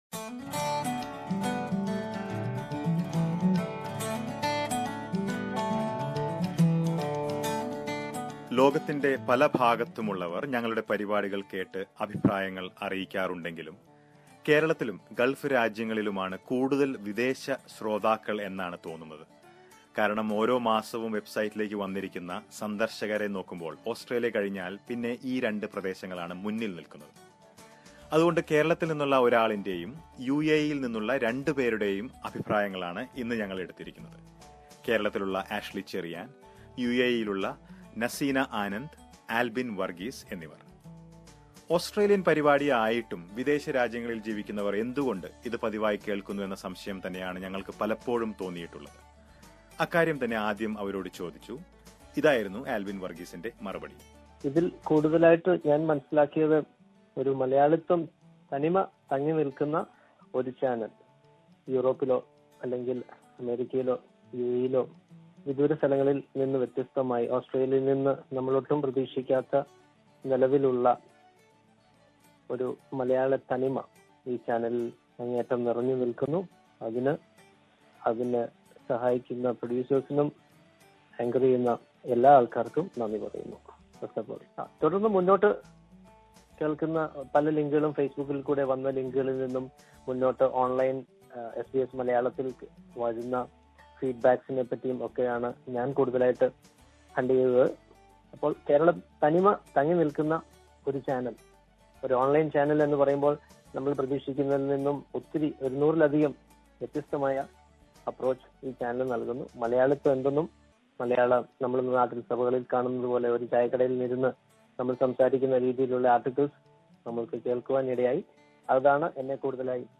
എന്തുകൊണ്ട് വിദേശത്തുള്ളവർ ഈ പരിപാടി കേൾക്കുന്നു. അക്കാര്യം വിദേശികളായ ശ്രോതാകൾ തന്നെ പങ്കുവയ്ക്കുന്നു.